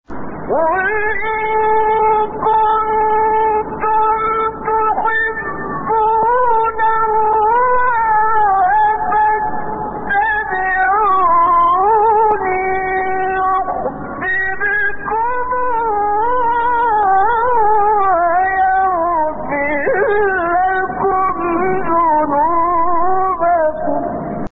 گروه شبکه اجتماعی: فرازهای صوتی از سوره آل‌عمران با صوت کامل یوسف البهتیمی که در مقام‌های مختلف اجرا شده است، می‌شنوید.
مقام بیات